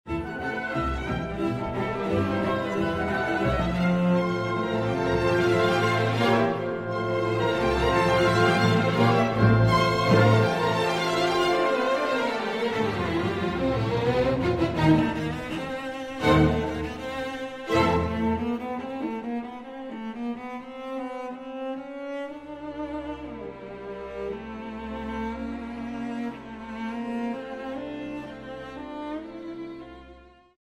ending with the slow-build hurricane